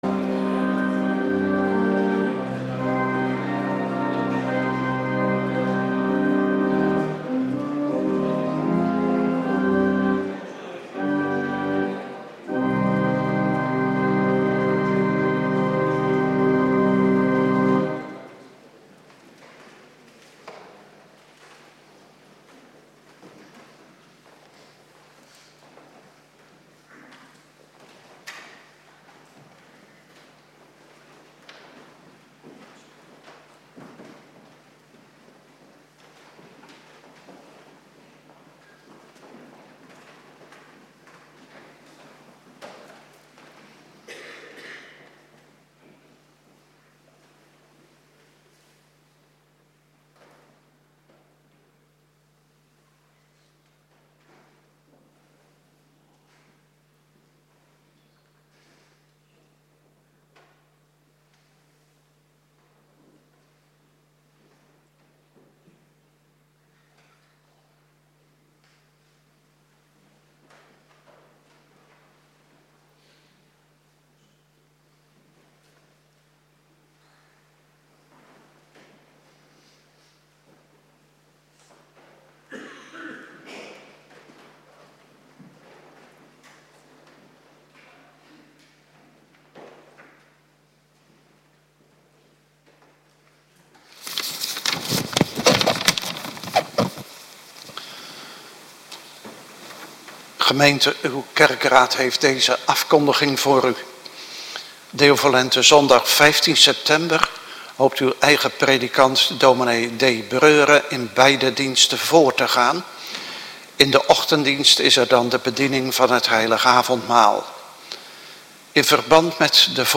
Avonddienst Voorbereiding Heilig Avondmaal
Locatie: Hervormde Gemeente Waarder